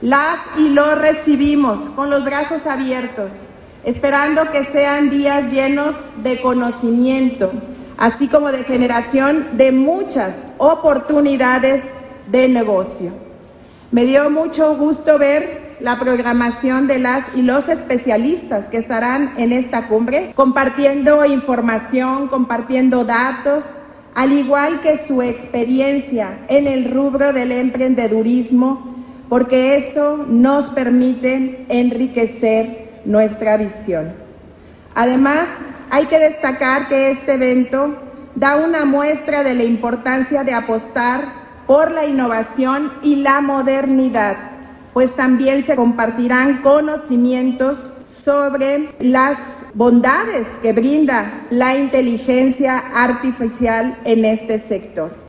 AudioBoletines
Lorena Alfaro García, presidenta municipal de Irapuato